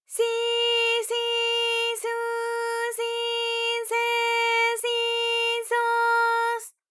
ALYS-DB-001-JPN - First Japanese UTAU vocal library of ALYS.
si_si_su_si_se_si_so_s.wav